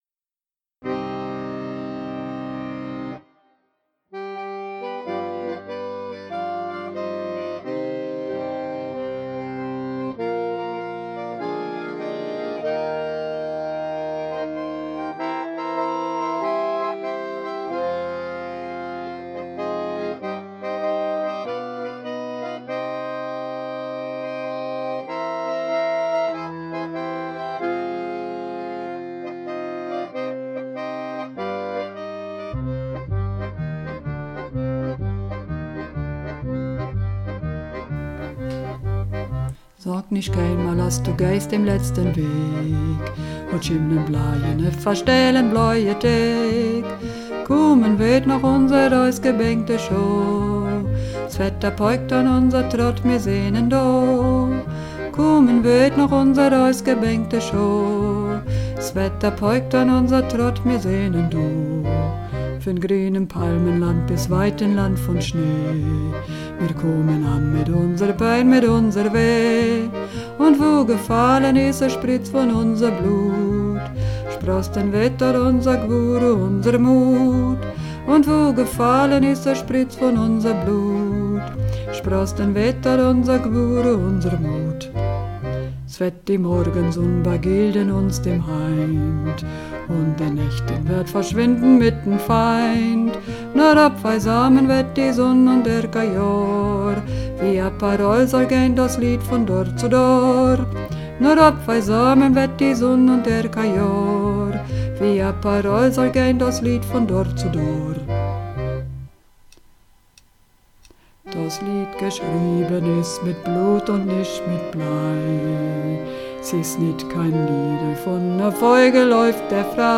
Runterladen (Mit rechter Maustaste anklicken, Menübefehl auswählen)   Sog nicht keijnmal (Bass)
Sog_nicht_keijnmal__2_Bass.mp3